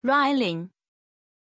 Aussprache von Rylyn
pronunciation-rylyn-zh.mp3